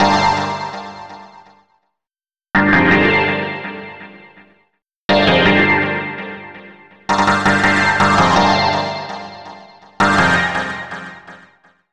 02_Quake_A_165bpm.WAV